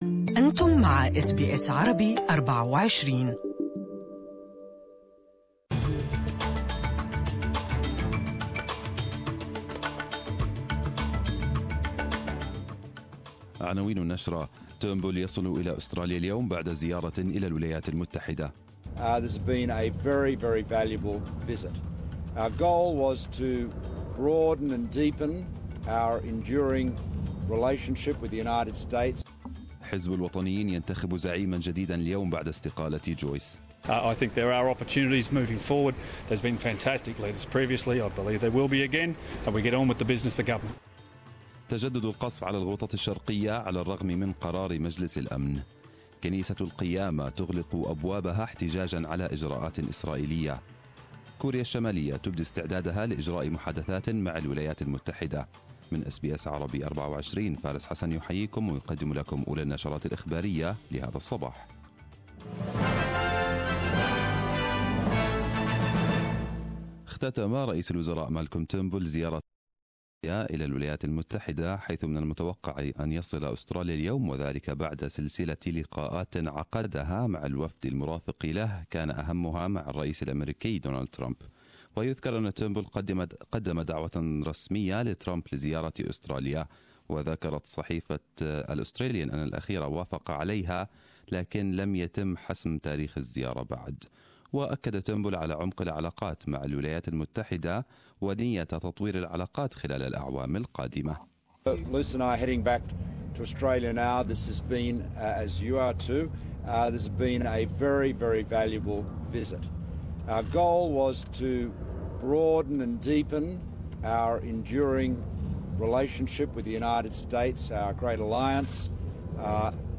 Arabic News Bulletin 26/02/2018